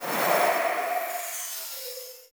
UI Whoosh Notification 1.wav